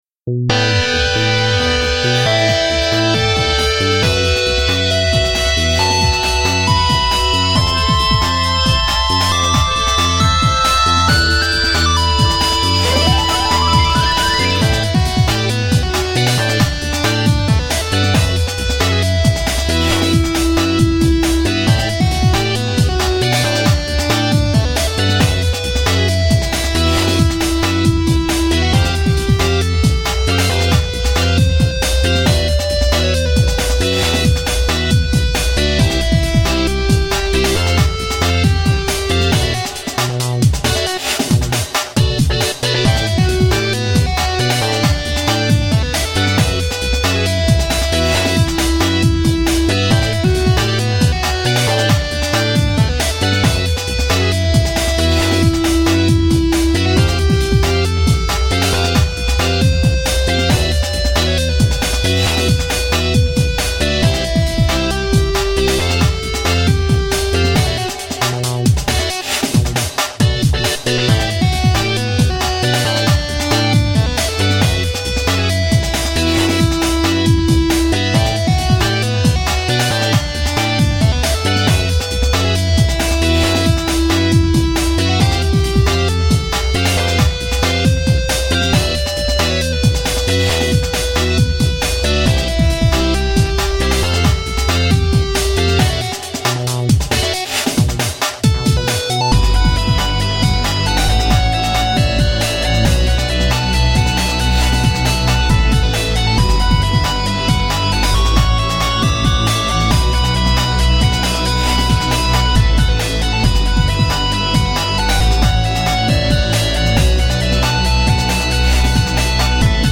DnB Remix)file